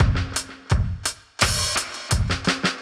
Index of /musicradar/dub-designer-samples/85bpm/Beats
DD_BeatB_85-02.wav